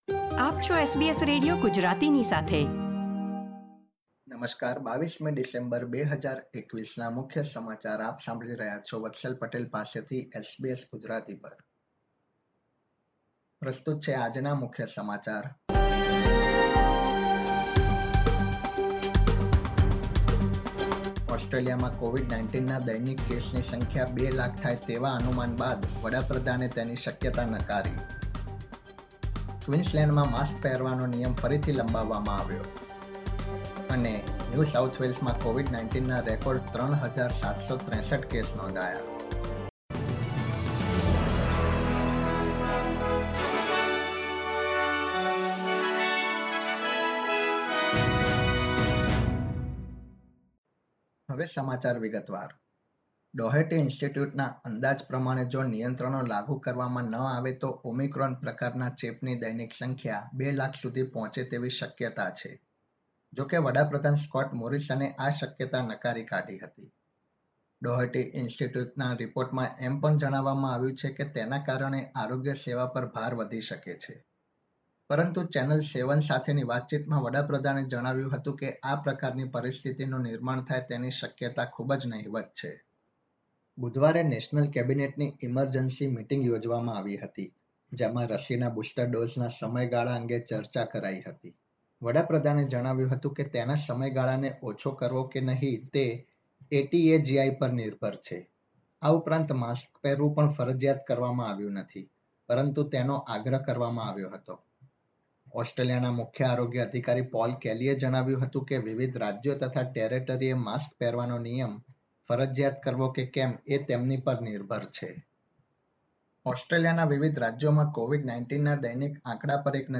SBS Gujarati News Bulletin 22 December 2021